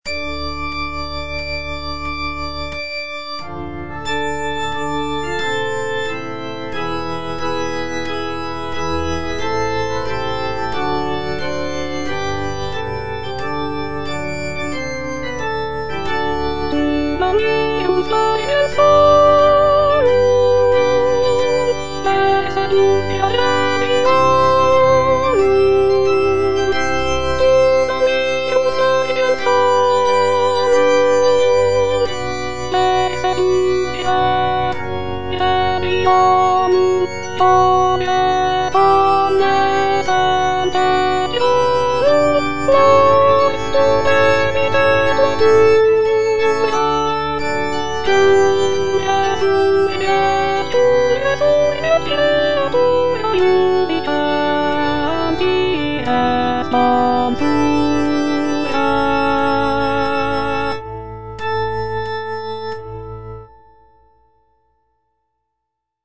Alto (Voice with metronome
is a sacred choral work rooted in his Christian faith.